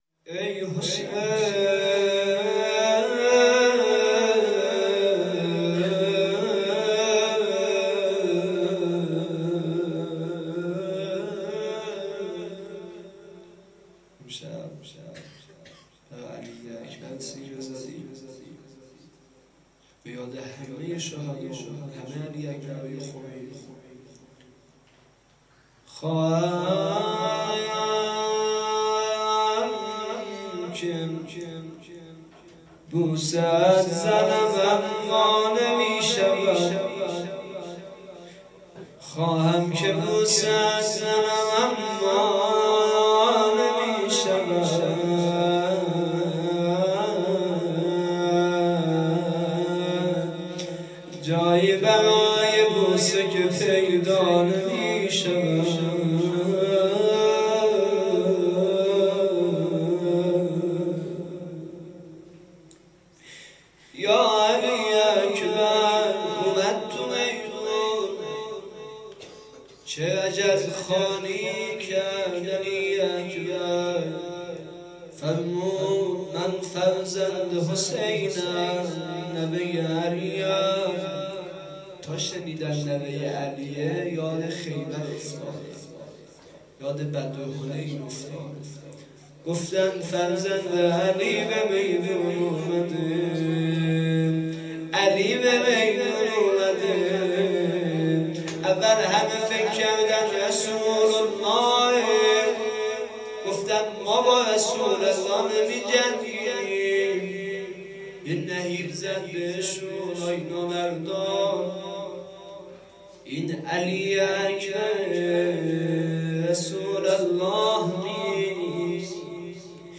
شب ۲۱ محرم 97
روضه